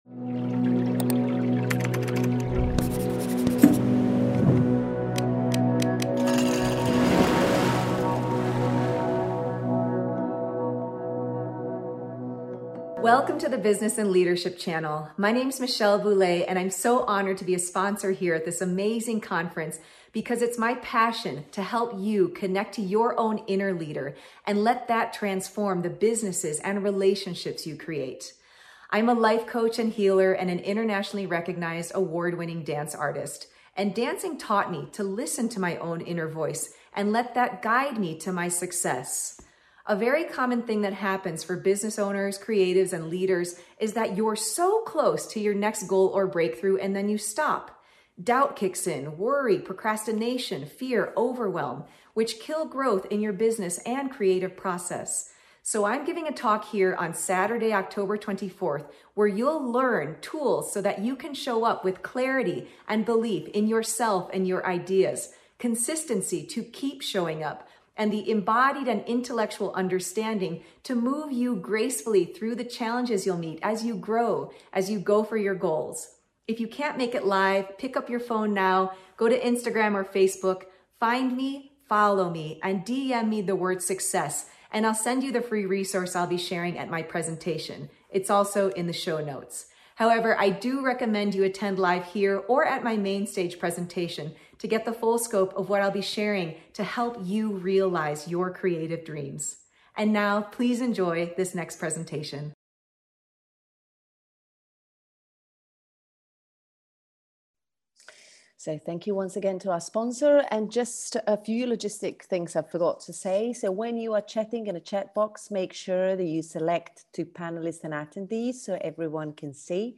Guided Practices